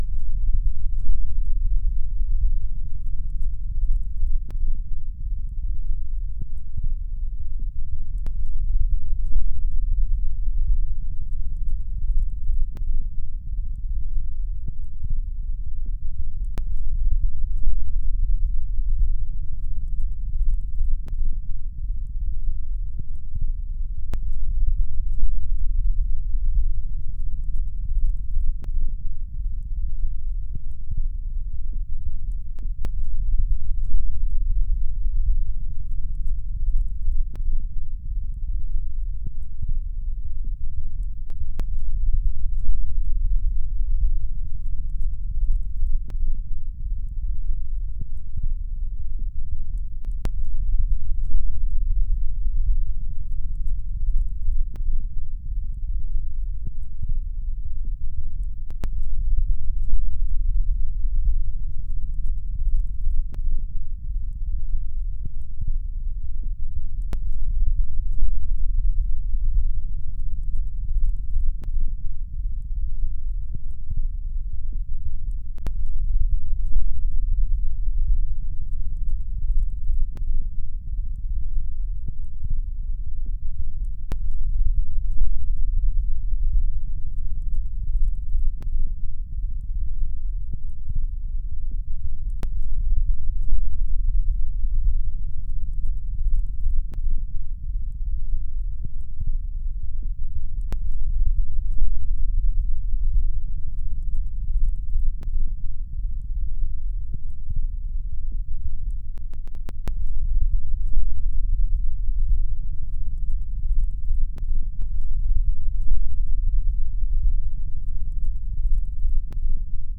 Klangbeispiele von Brummtönen, binaurale Beats und gefiltertem Rauschen, Sound Beispiel Brummton Nachbildung.
Aufgrund der niedrigen Frequenzen sind die Sound-Beispiele am besten mit einem Kopfhörer oder mit dementsprechend großen Lautsprechern über eine Stereoanlage wiederzugeben.